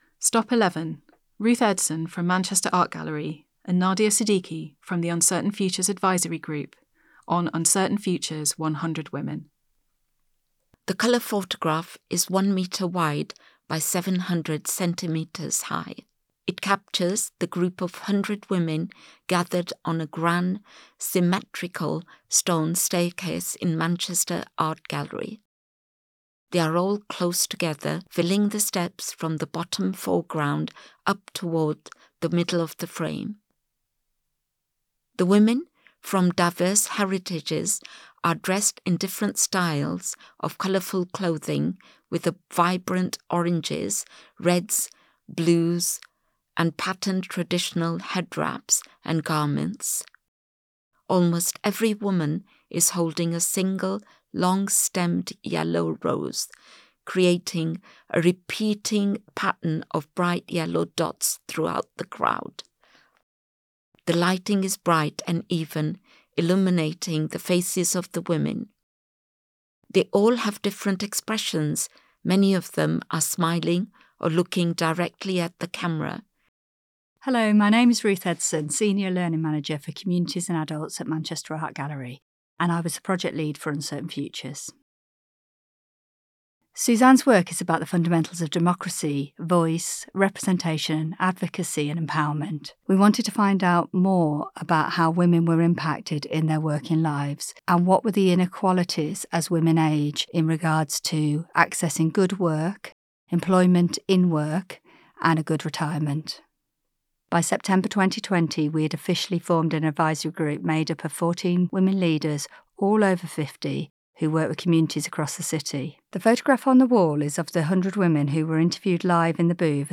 Explore our exhibitions using your own device, with audio description, British Sign Language and gallery captions